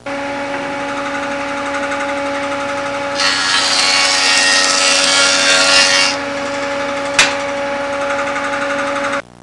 Electric Saw Sound Effect
Download a high-quality electric saw sound effect.
electric-saw.mp3